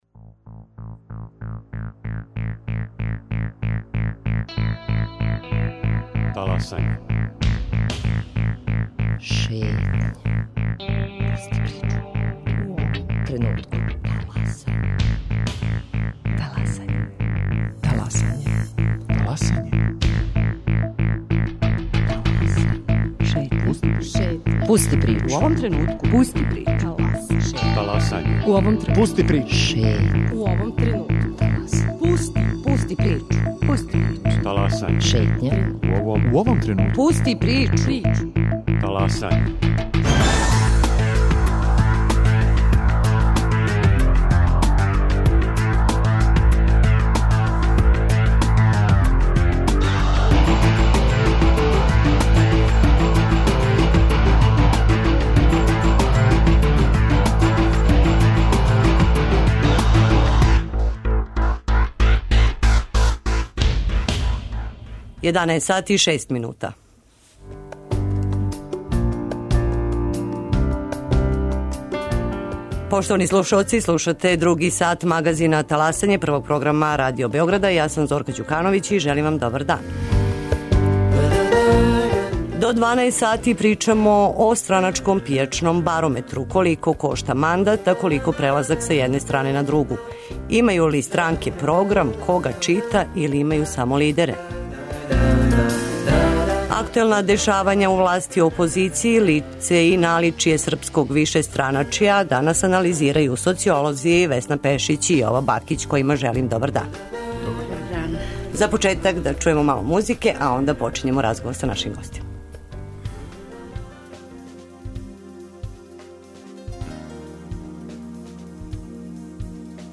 Актуелна дешавања у опозицији и лице и наличје српског вишестраначја, анализирају социолози Весна Пешић и Јово Бакић.